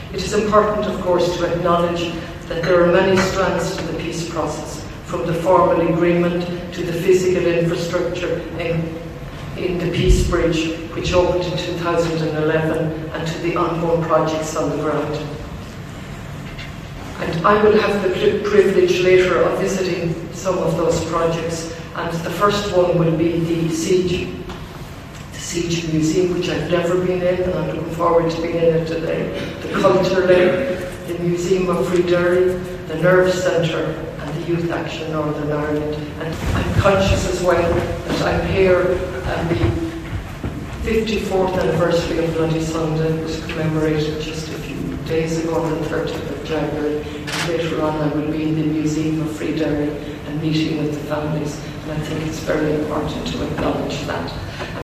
President Catherine Connolly gives a civic address in Derry
This morning, she arrived at the Guildhall to deliver a civic address.